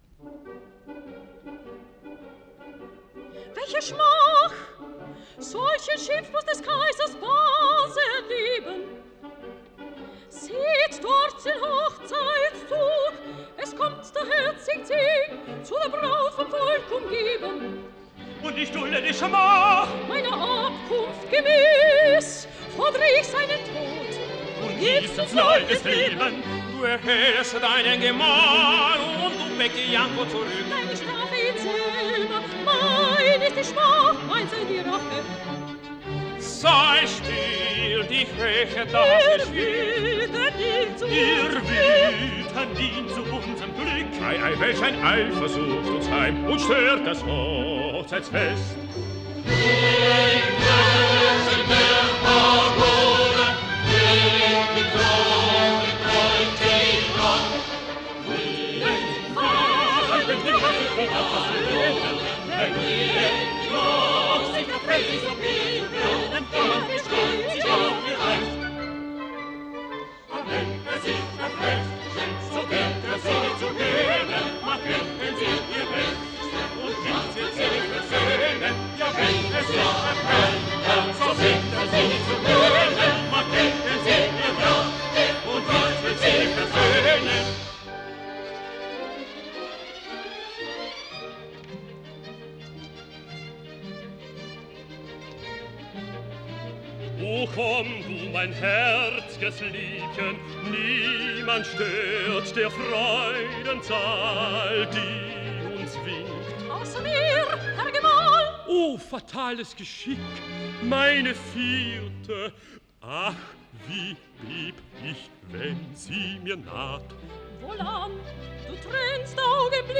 an opéra-féerique in three acts